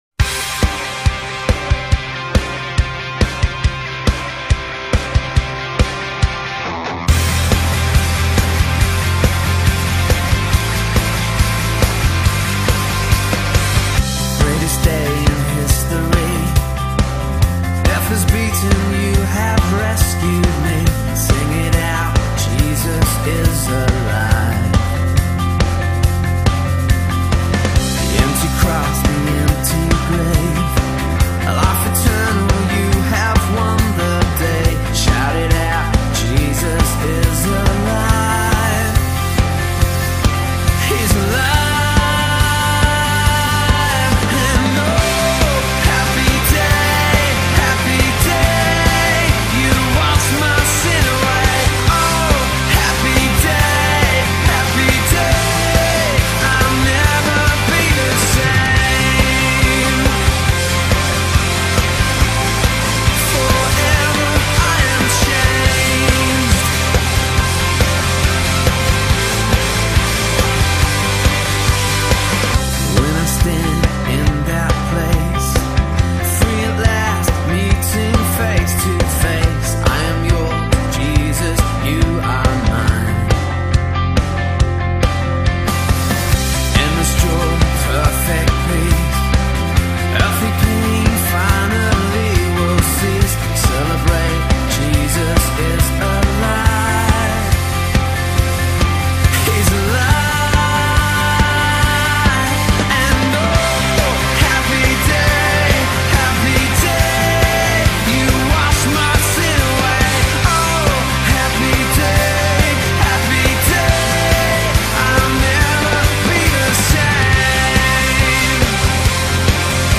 Northview 05/30/2021
Songs of Praise and Worship